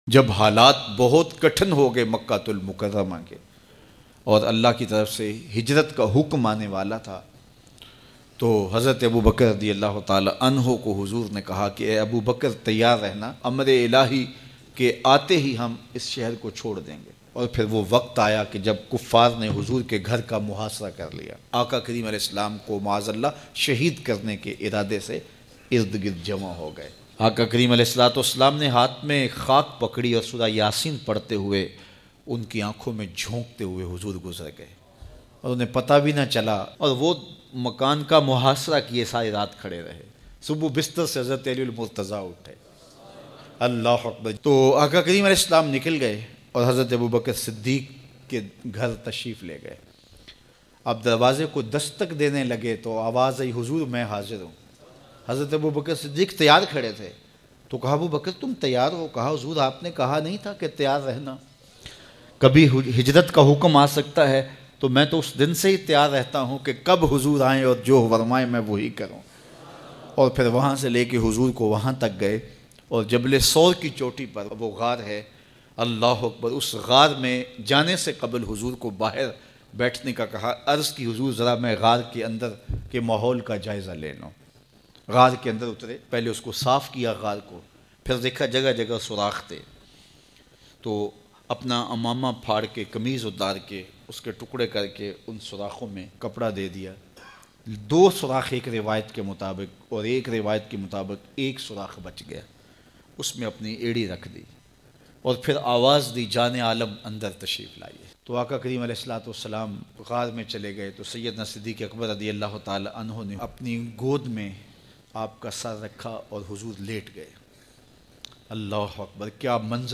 Emotional Bayan mp3.